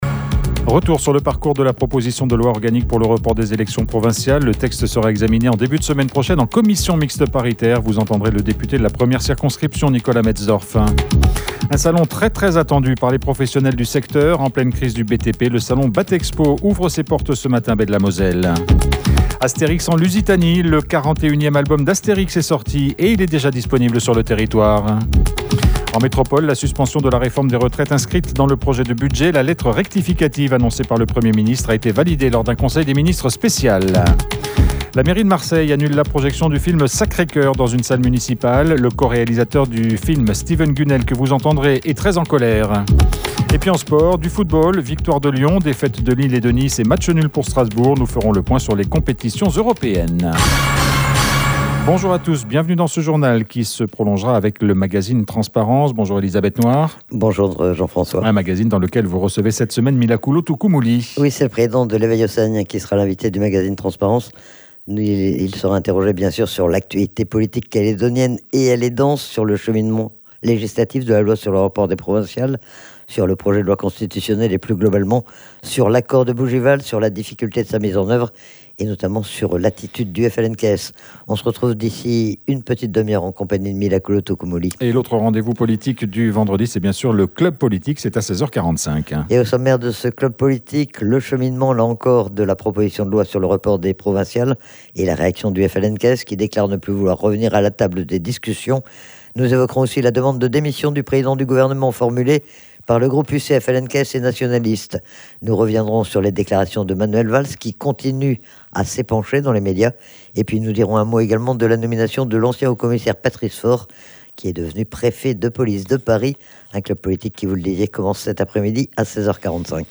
Vous entendrez Nicolas Metzdorf, qui était notre invité ce matin.